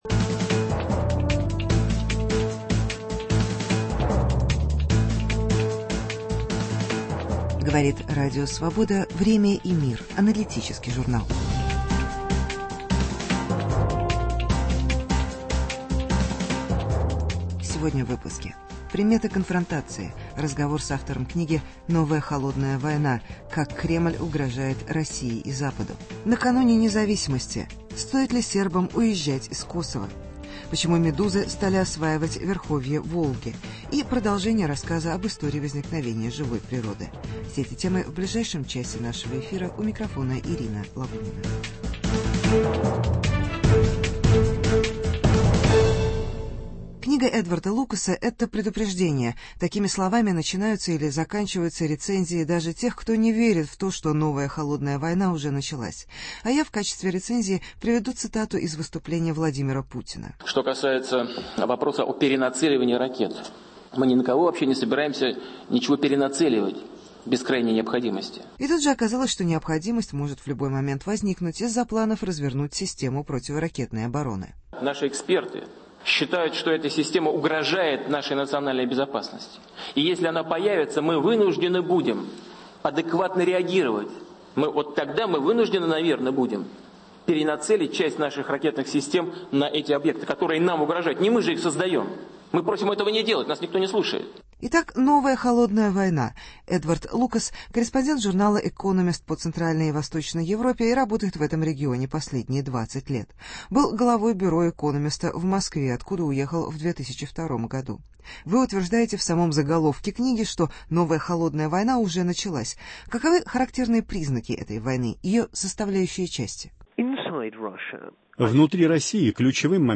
Началась ли новая холодная война? Интервью с Эдвардом Лукасом.